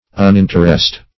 Uninteressed \Un*in"ter*essed\, a.